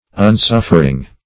Search Result for " unsuffering" : The Collaborative International Dictionary of English v.0.48: Unsuffering \Un*suf"fer*ing\, n. Inability or incapability of enduring, or of being endured.